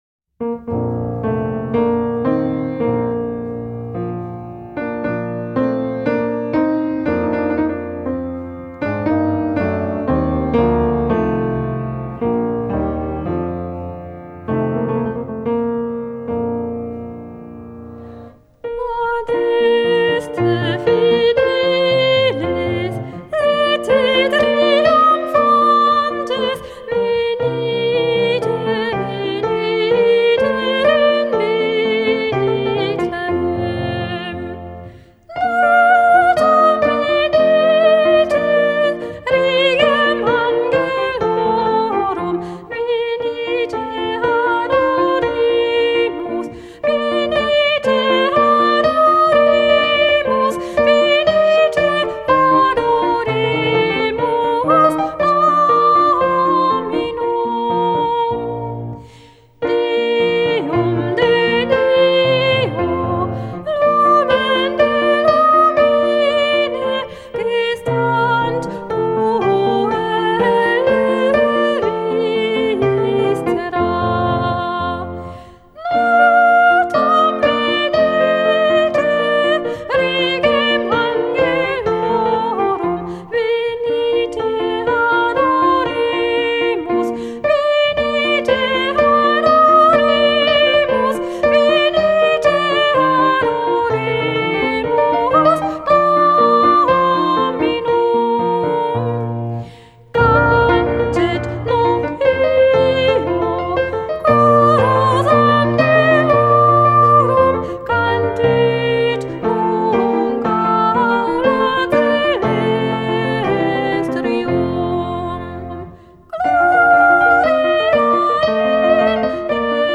Genre: Weihnachtsmusik